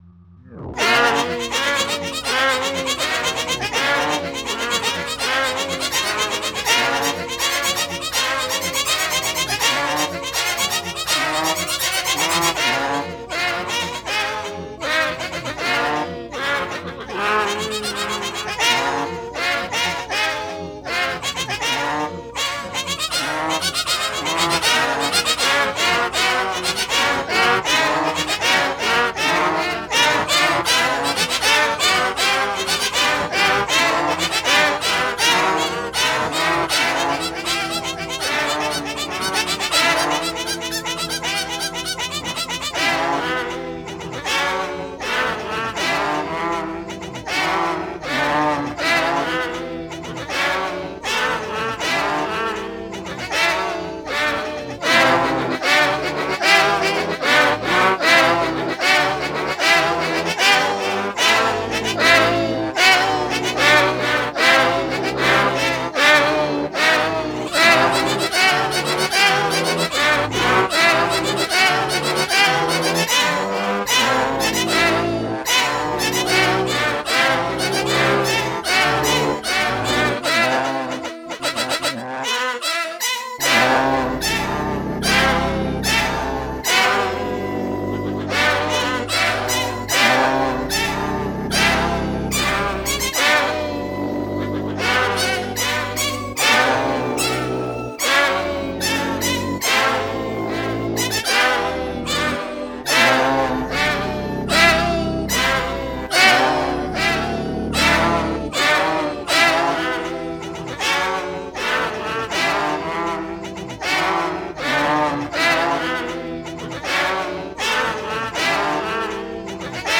Meow